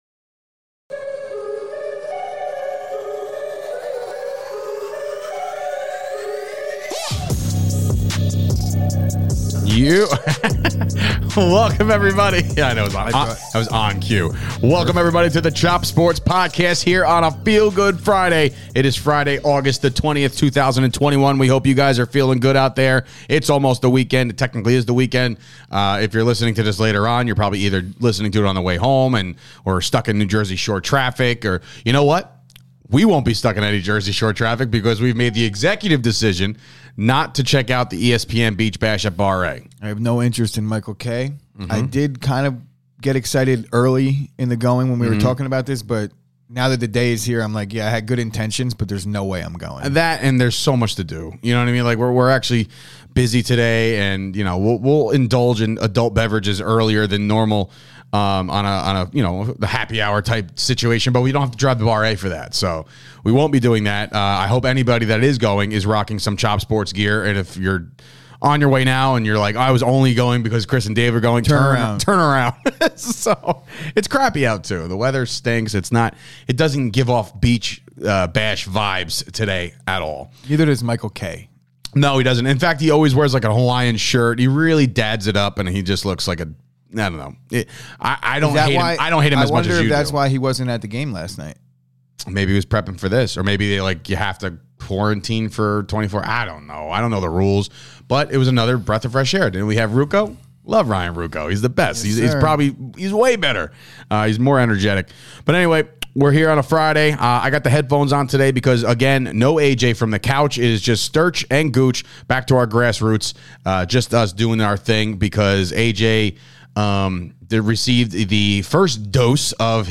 it's a 2 man show